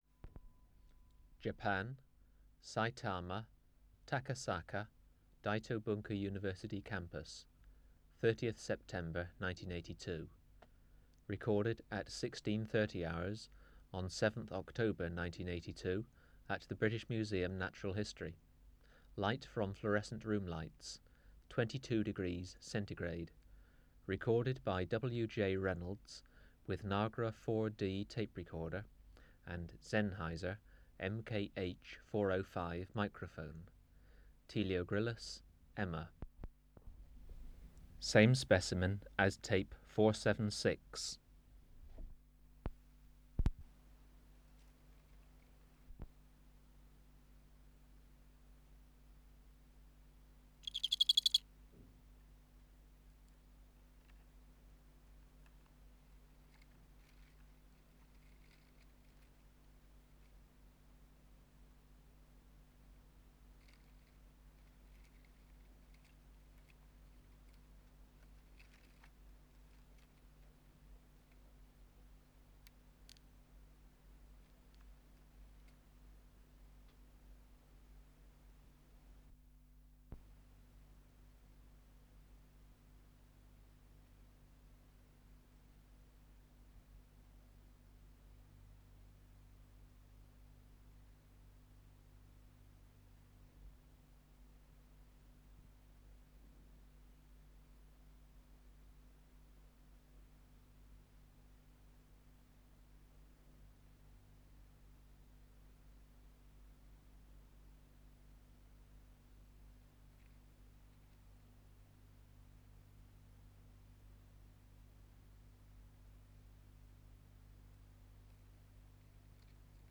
Natural History Museum Sound Archive Species: Teleogryllus